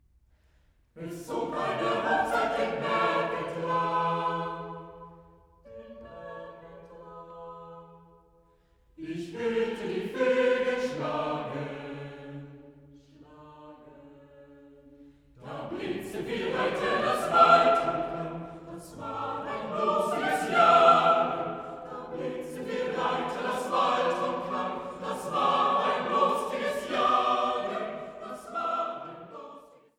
Komposition für gemischten Chor